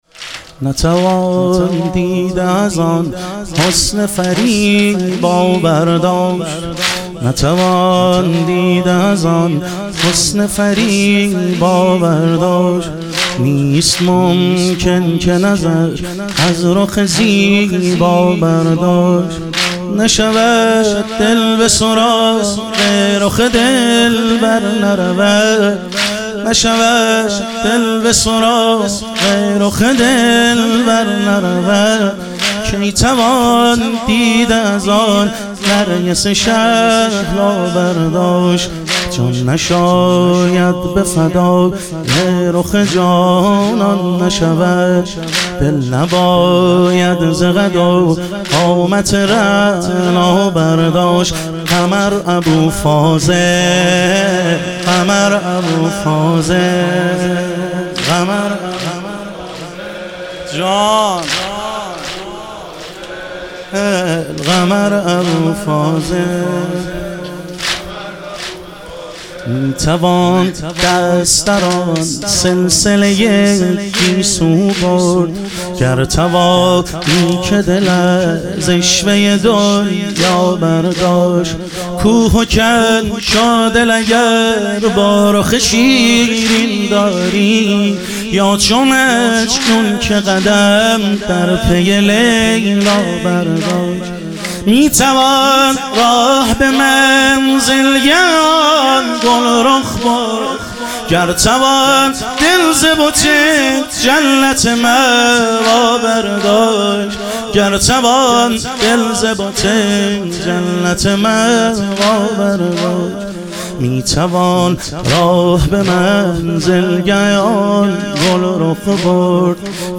شهادت حضرت سلطانعلی علیه السلام - واحد